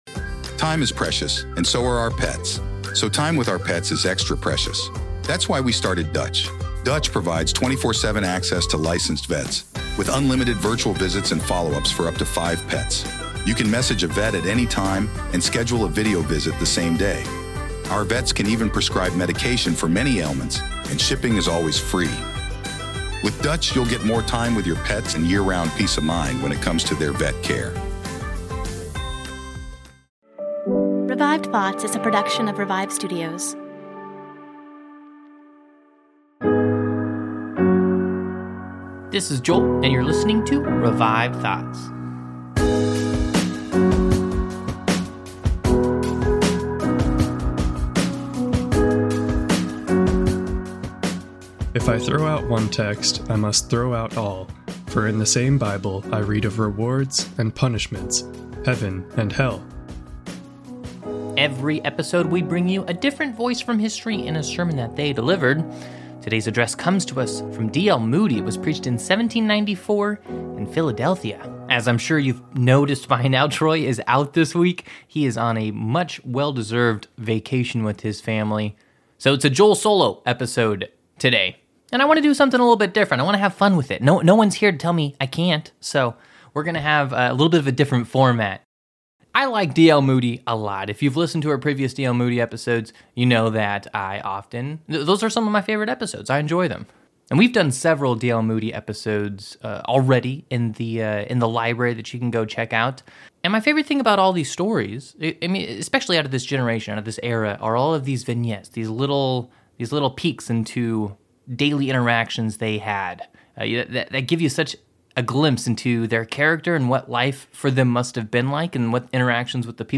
DL Moody is one of the most famous evangelists of the 19th century. Listen to some anecdotes from his life and a sermon he delivered on the subject of Hell....